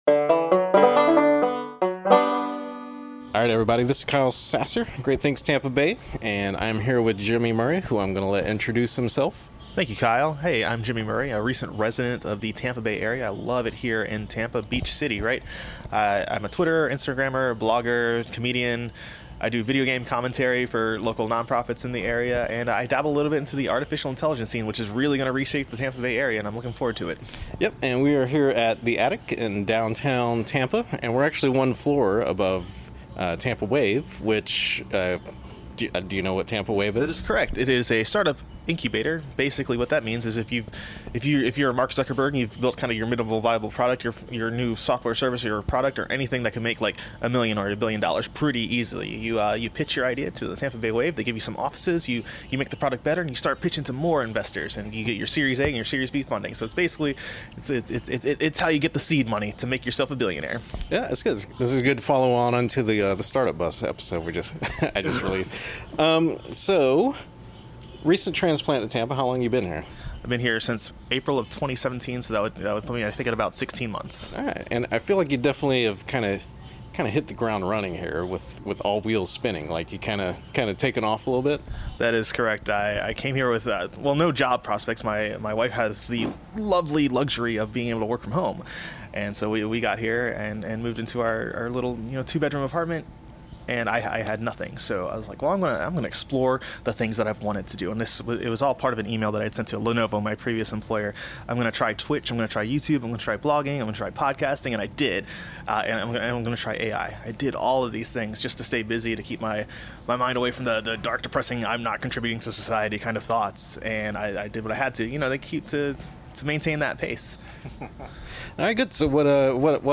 We sit down an have a chat at The Attic, located in beautiful downtown Tampa, Florida.